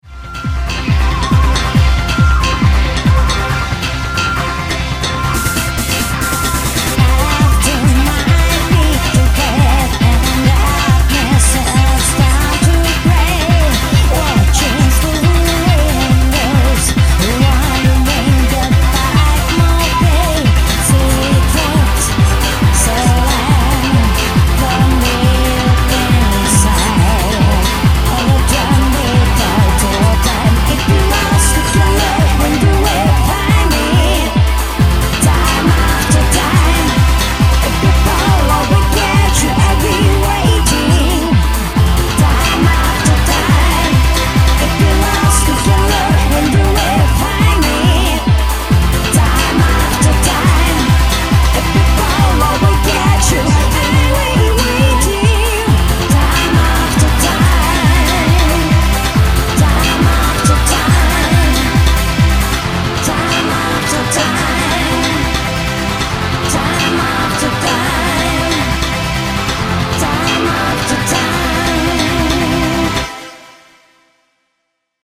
パチンコ機器やゲームテーマの歌入れはけっこう参加してたんデス (*1)